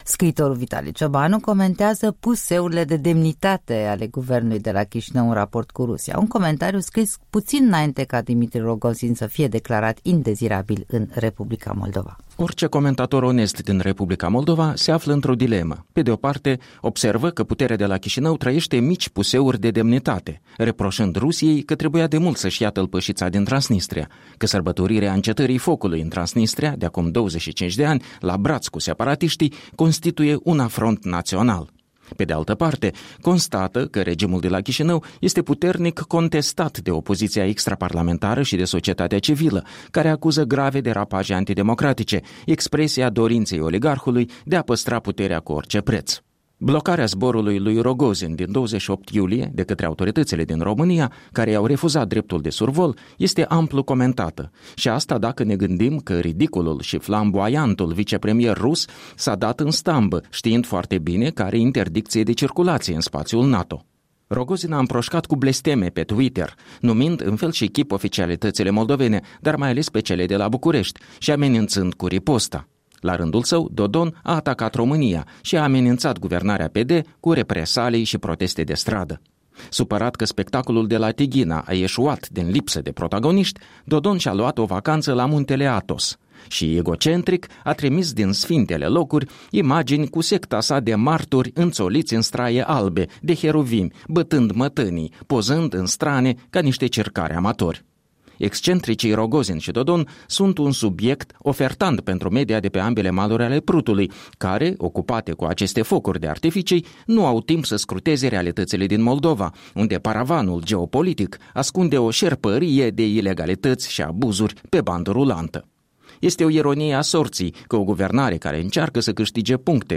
Un punct de vedere săptămânal la Europa Liberă.